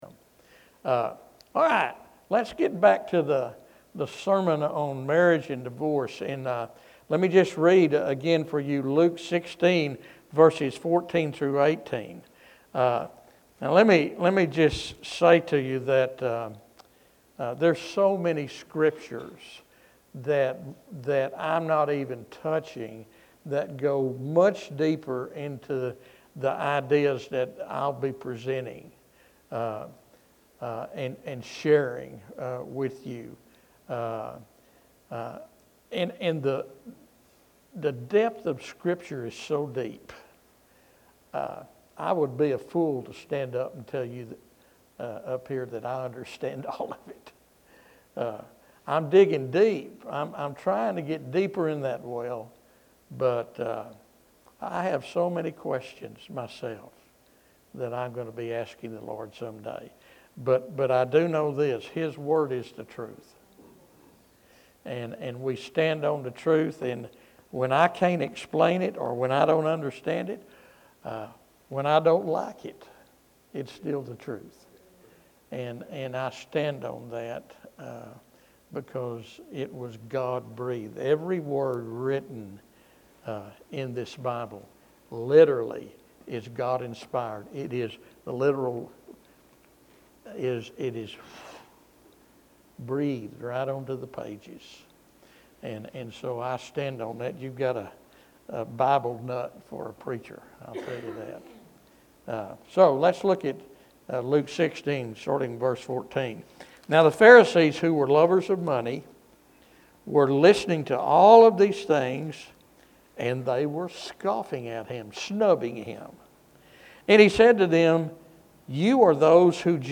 This message offers both truth and compassion—upholding the biblical view of marriage as a sacred covenant while extending grace to those who have experienced the tragedy of divorce. Whether you're married, divorced, or walking alongside someone going through this difficult journey, this sermon provides biblical wisdom and pastoral care.